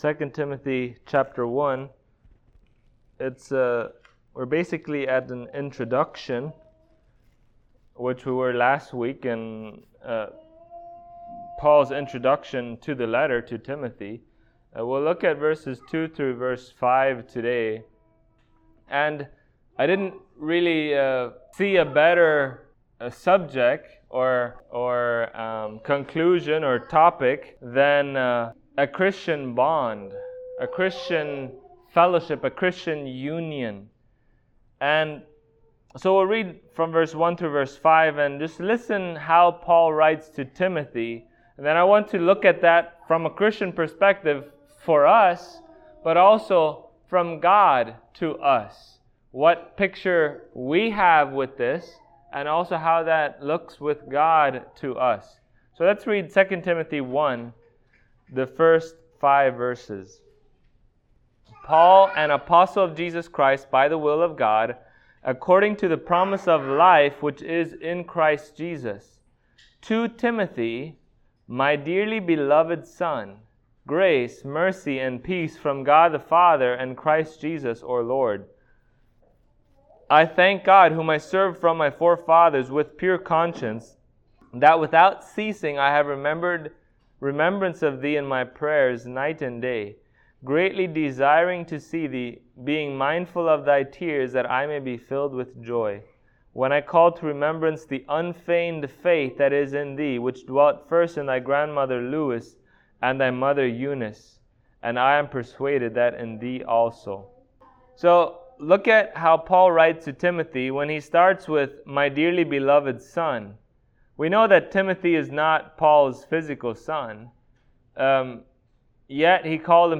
2Timothy 1:2-5 Service Type: Sunday Morning There is a true bond of love and peace between true Believers.